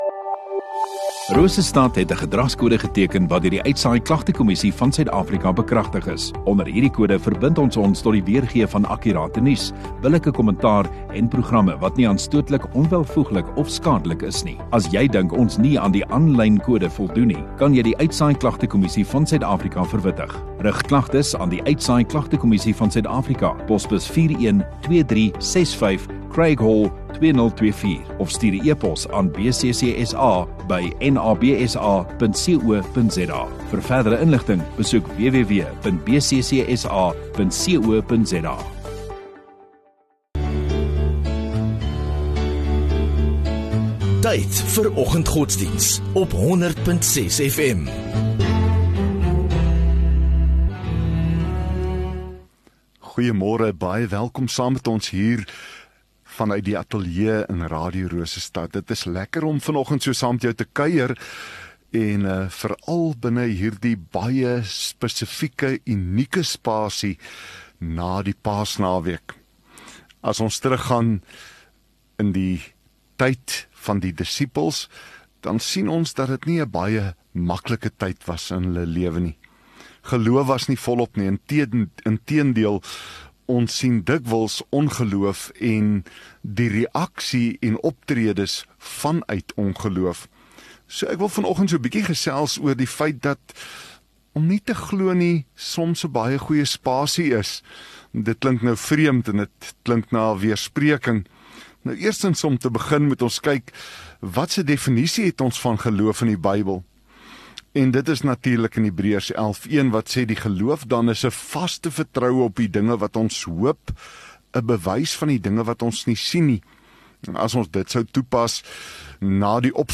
MultiMedia LIVE View Promo Continue Install Rosestad Godsdiens 22 Apr Dinsdag Oggenddiens